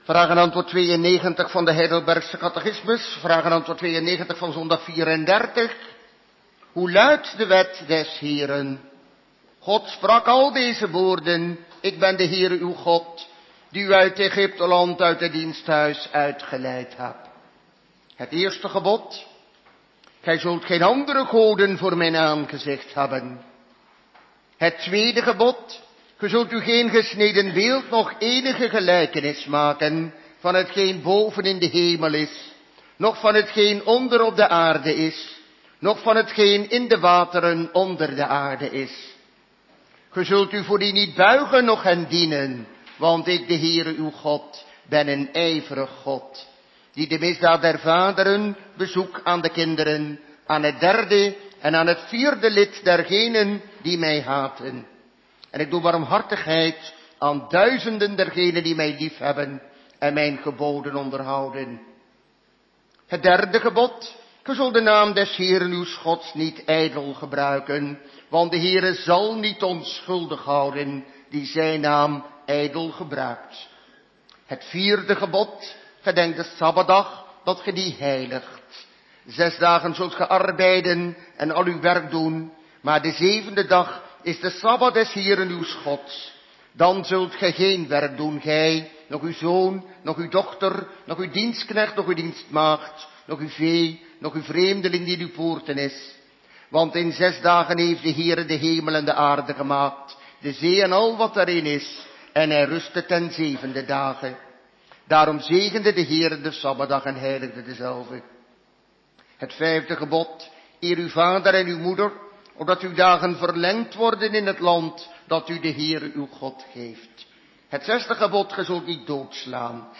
LuisterenWanneer een kerkdienst live is wordt de uitzending hieronder weergegeven - Page 42
Heidelbergse Catechismus HC Zondag 35 vr. en antw. 97 19 augustus 2018 Predikant